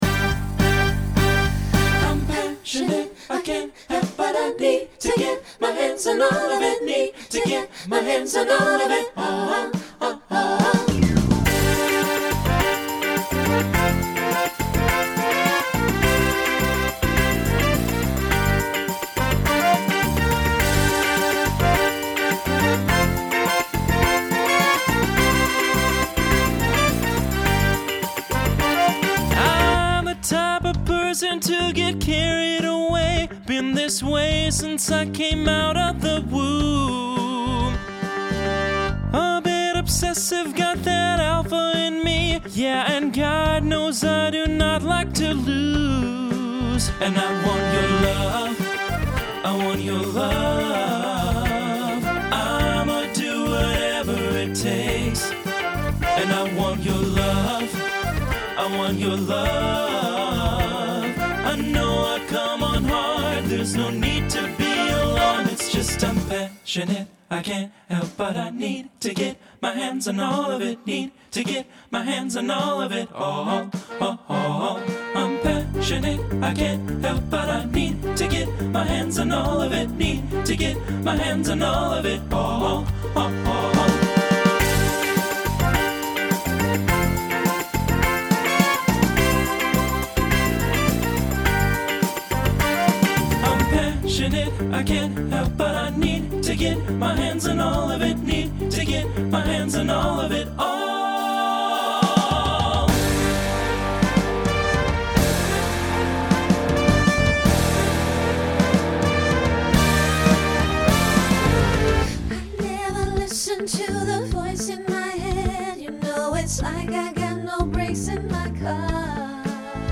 Starts SATB, then TTB, then SSA, then finishes SATB.
Voicing Mixed